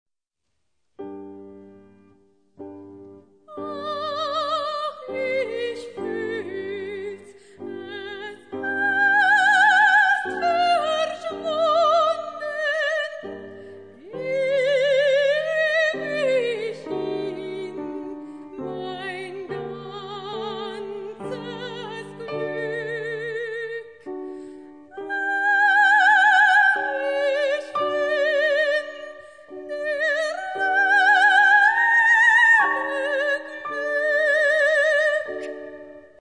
Sopran
Flügel